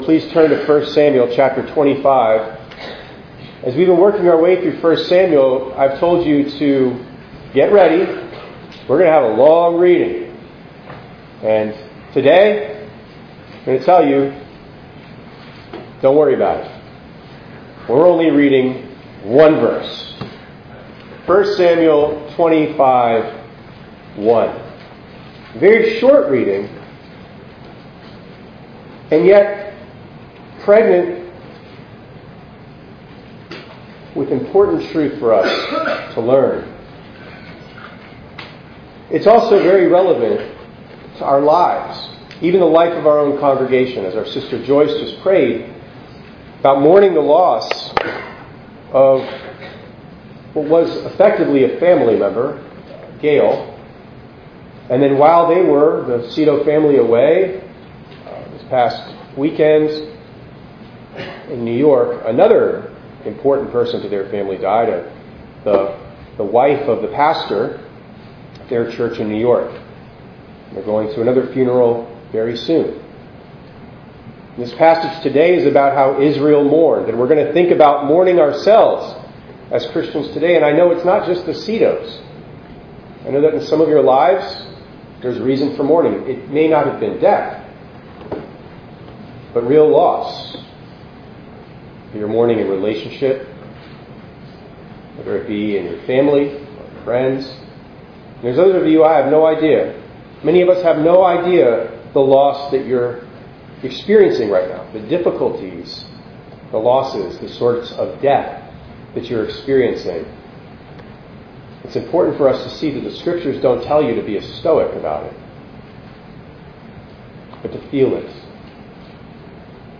11_9_25_ENG_Sermon.mp3